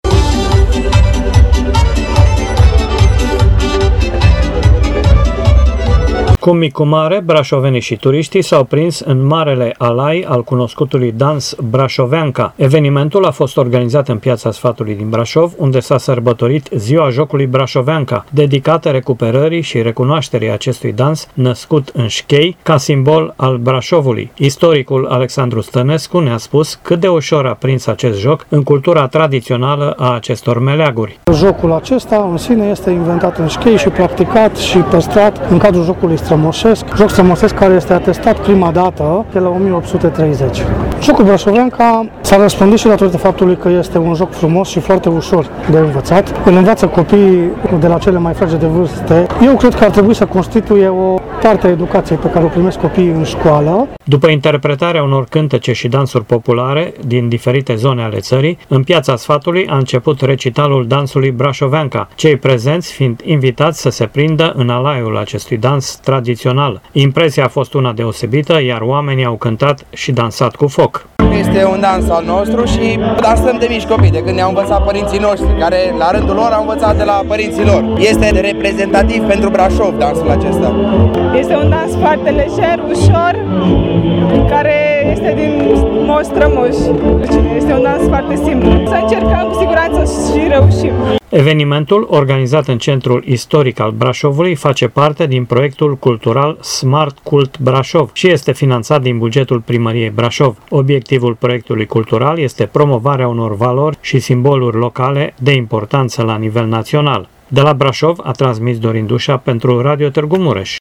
Evenimentul a fost organizat în Piața Sfatului din Brașov, unde s-a sărbătorit ,,Ziua Jocului Braşoveanca”, dedicată recuperării şi recunoaşterii acestui dans, născut în Schei, ca simbol al Braşovului.
Impresia a fost una deosebită, iar oamenii au cântat și dansat cu foc.